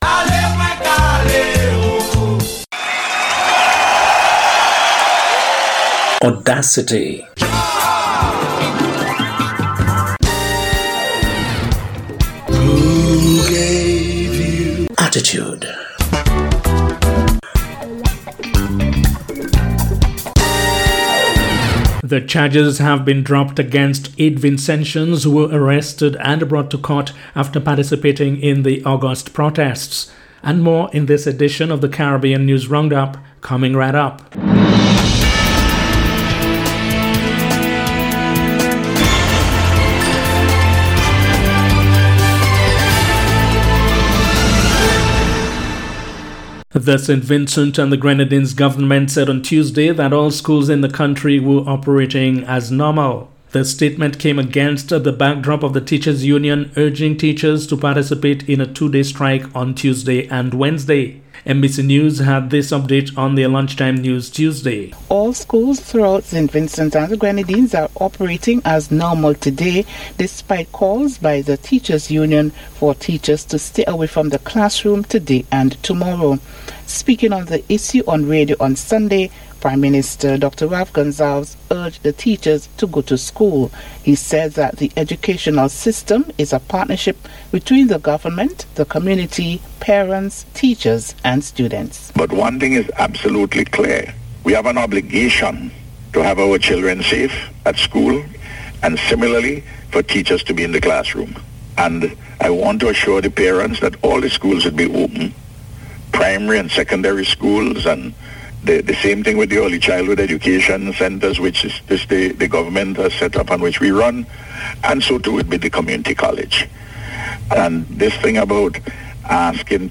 December 8th Wednesday Caribbean News Roundup
December-8th-Wednesday-Caribbean-News-Roundup.mp3